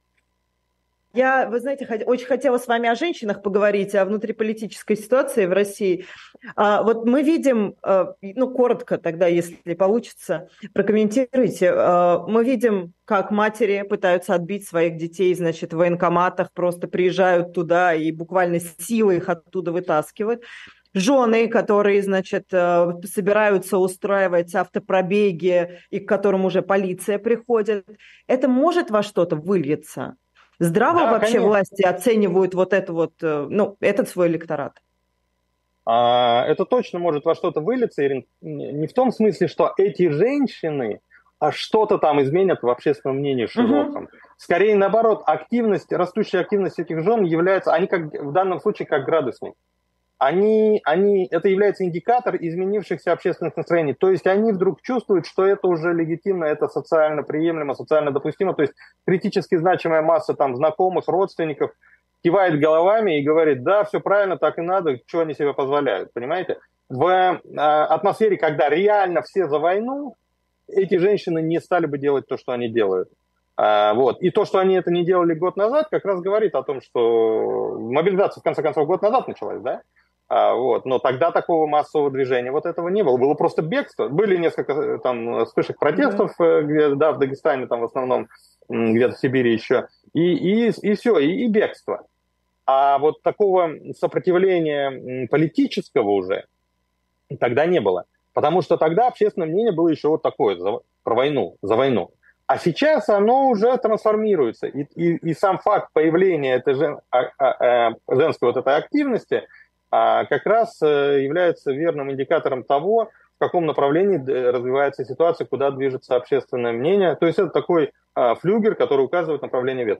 Фрагмент эфира от 04.12.23